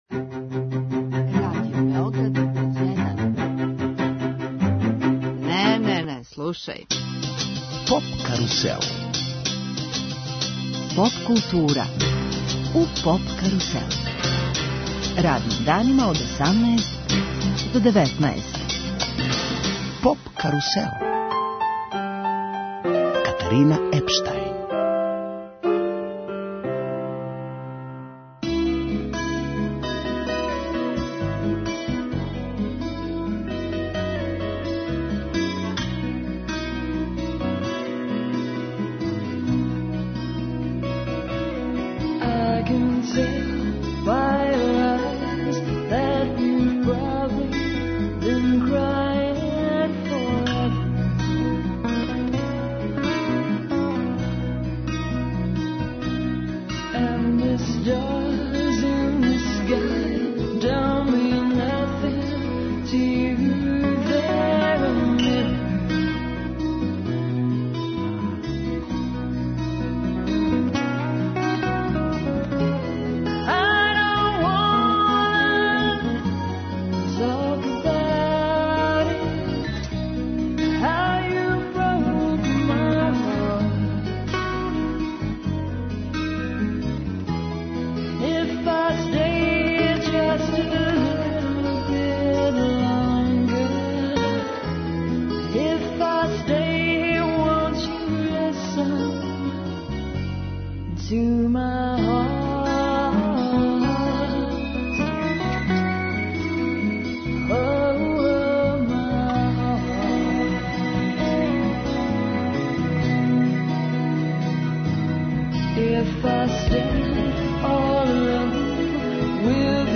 Гост емисије је један од најзначајнијих светских саксофониста Илхан Ерсахин.